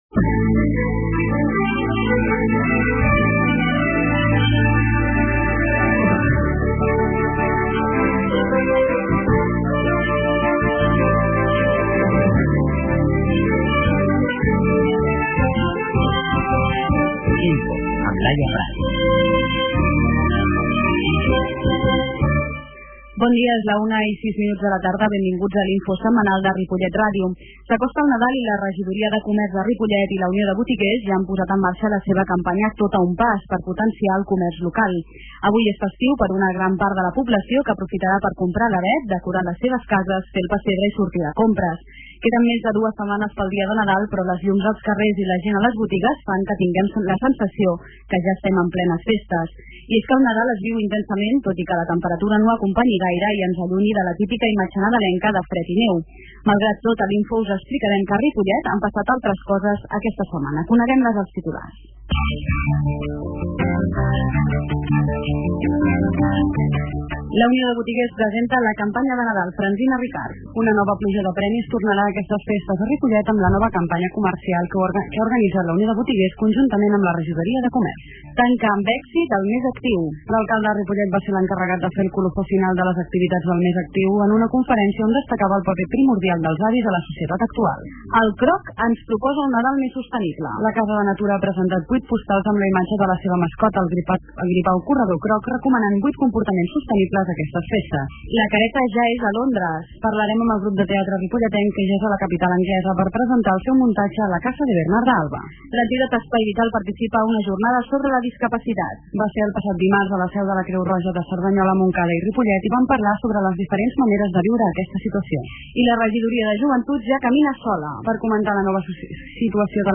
Aquesta setmana amb l'entrevista al regidor de Cultura i Joventut, Ferran Tornel.
La qualitat de so ha estat redu�da per tal d'agilitar la seva desc�rrega.